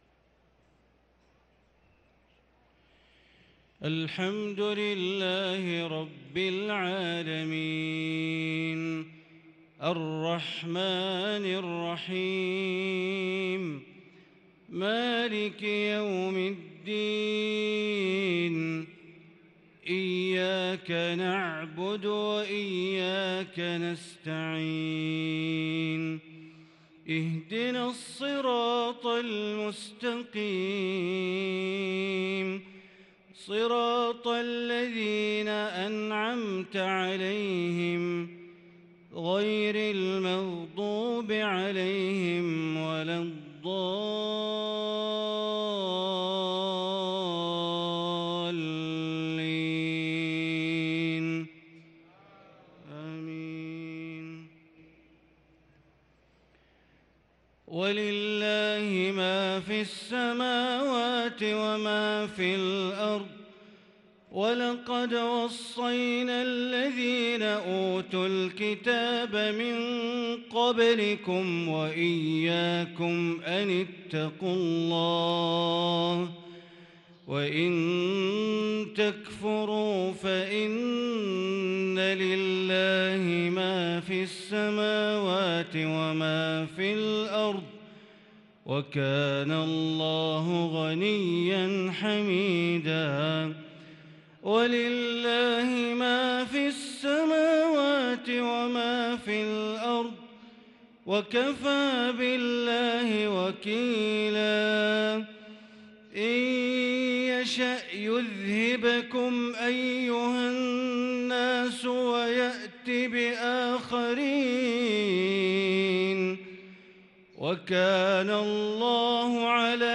صلاة العشاء للقارئ بندر بليلة 6 جمادي الأول 1444 هـ
تِلَاوَات الْحَرَمَيْن .